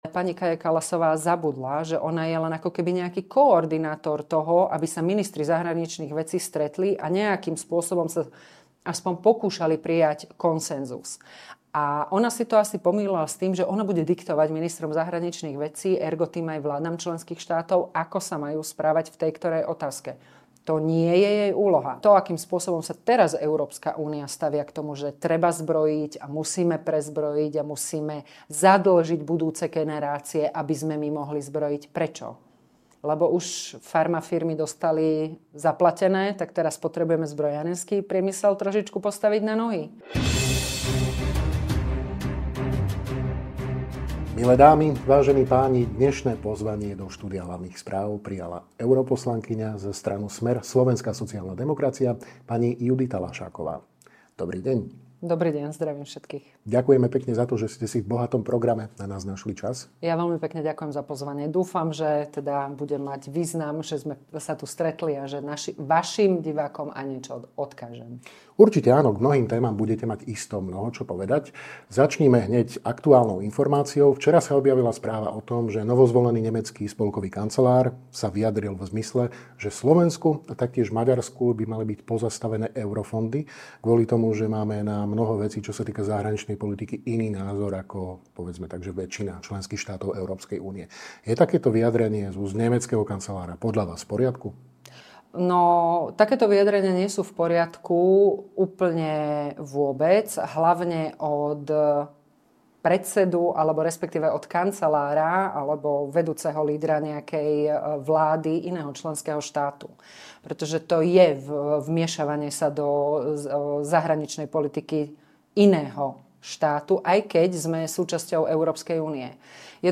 Vyjadrenia kancelára Merza aj euroúradničky Kallasovej, stovky miliárd na prezbrojenie EÚ, fungovanie Ukrajiny za Zelenského, absencia skutočných európskych lídrov, ale aj sloboda slova a úroveň novinárčiny nielen na Slovensku. To sú hlavné témy rozhovoru s europoslankyňou za SMER – SSD, JUDr. Juditou Laššákovou